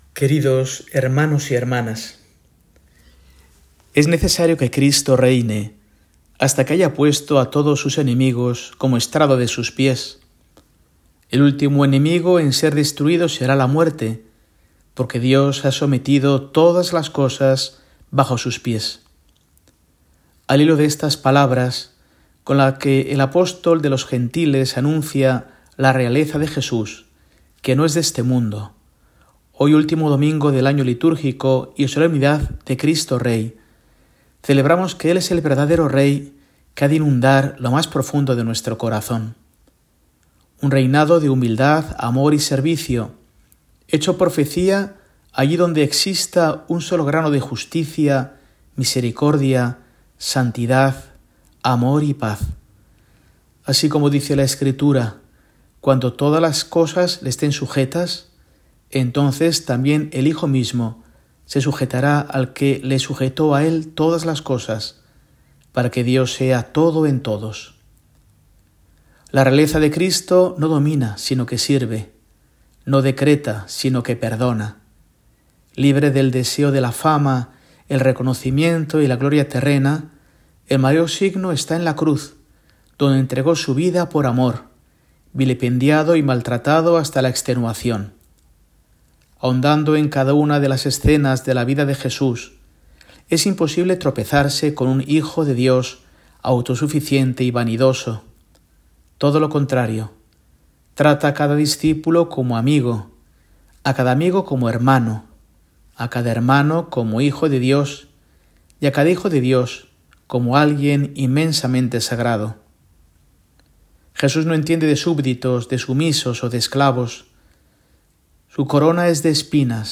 Mensaje semanal de Mons. Mario Iceta Gavicagogeascoa, arzobispo de Burgos, para el domingo, 24 de noviembre, solemnidad de Cristo Rey